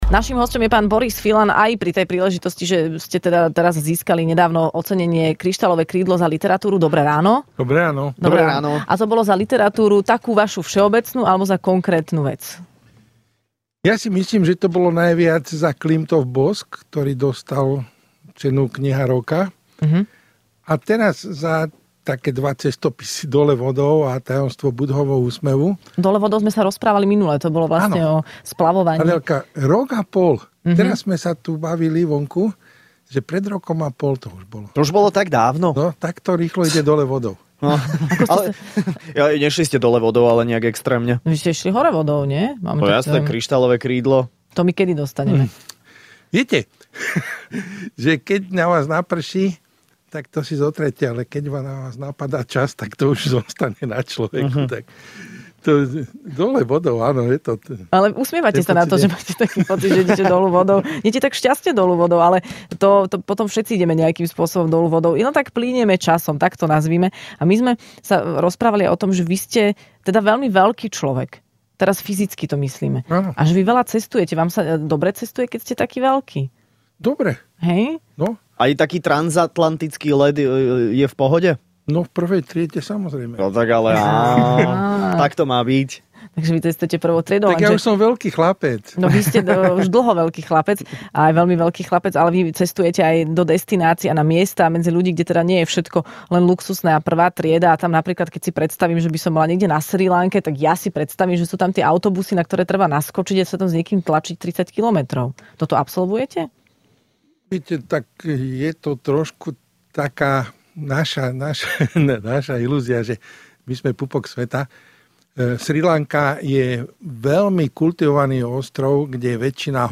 Hosťom v Rannej šou bol cestovateľ a spisovateľ Boris Filan, ktorý dostal aj ocenenie